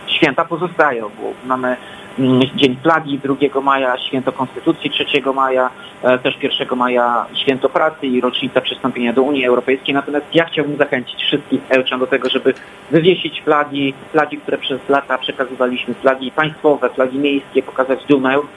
– Brak oficjalnych i tych mniej oficjalnych obchodów nie oznacza, że święta w tym roku zostały odwołane – mówi Tomasz Andrukiewicz, prezydent Ełku.